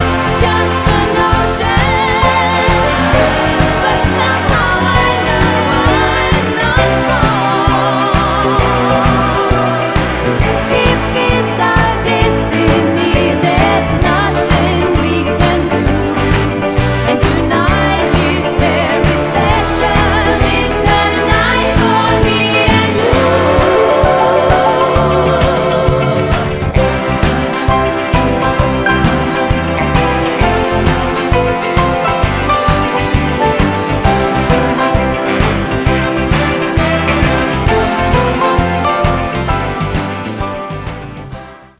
поп
женский вокал
70-е